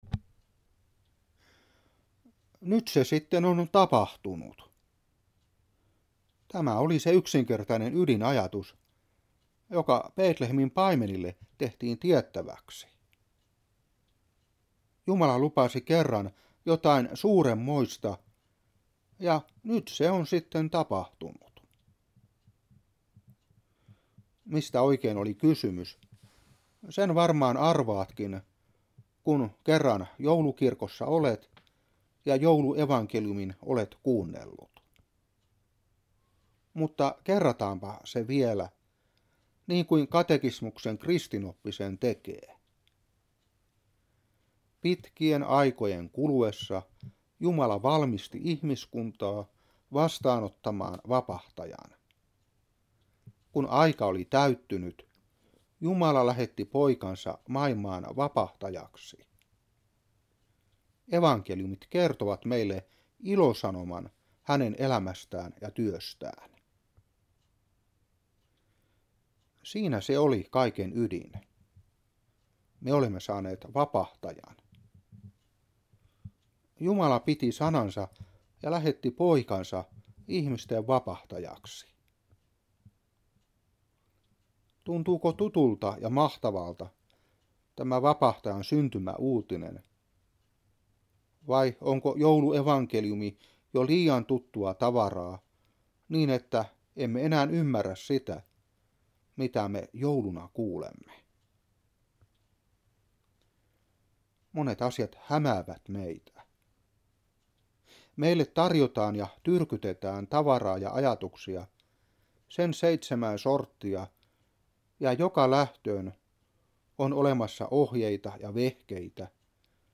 Saarna 1995-12.